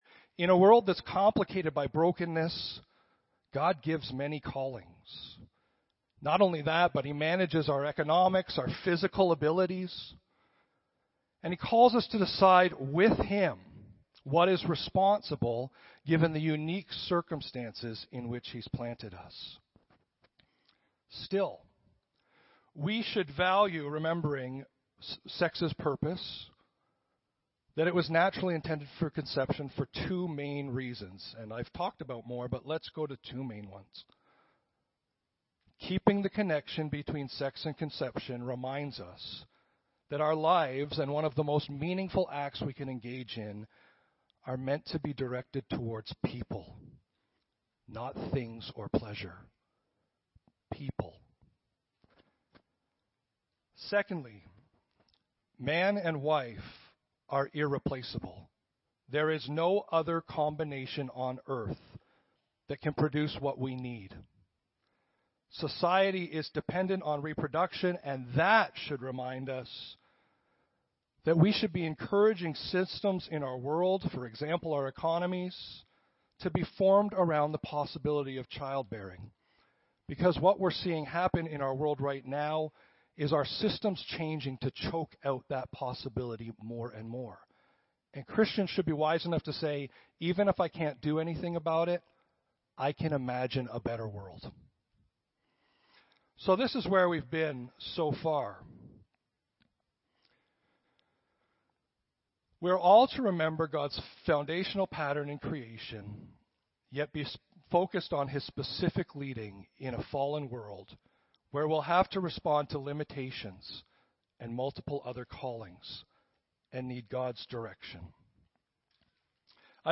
Audio Sermons - Clive Baptist Church